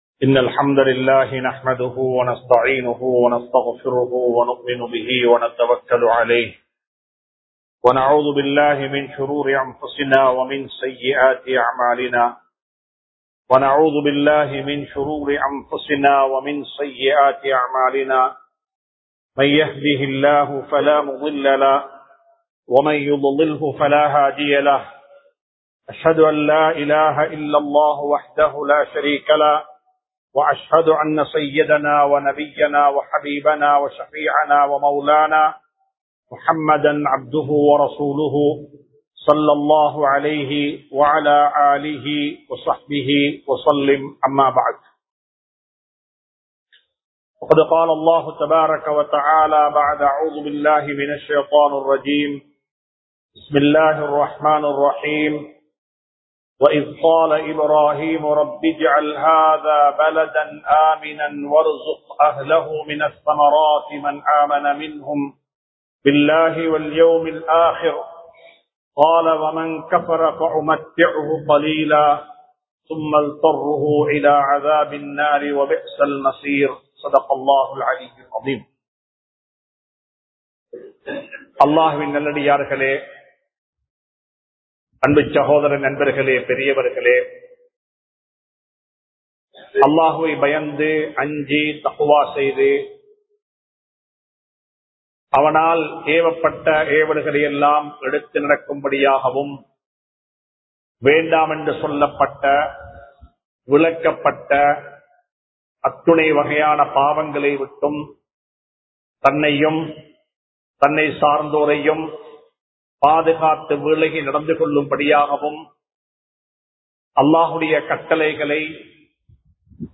சமூகத்திற்கு நாம் செய்ய வேண்டியவை | Audio Bayans | All Ceylon Muslim Youth Community | Addalaichenai
Madawakkulam Grand Jumuah Masjith